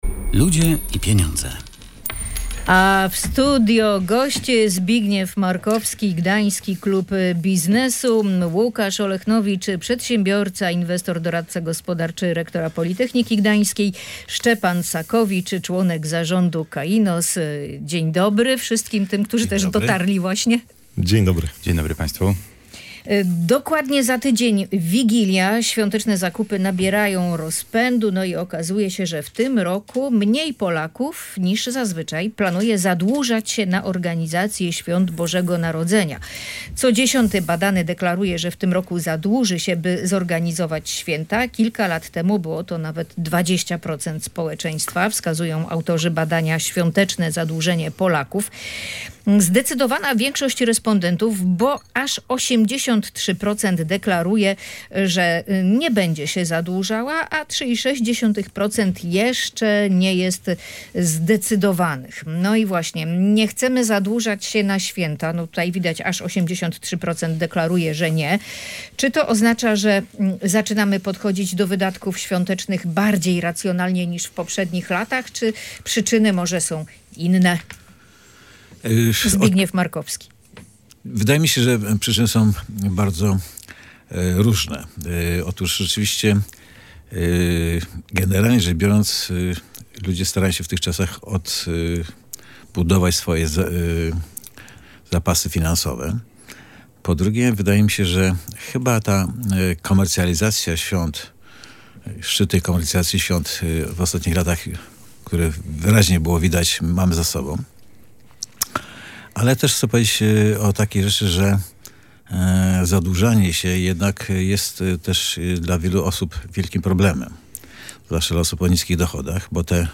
Mamy na Pomorzu wszystko, aby w optymalny sposób wykorzystać komputer kwantowy, który w ciągu dwóch lat chce zbudować Politechnika Gdańska – mówili goście audycji „Ludzie i Pieniądze”.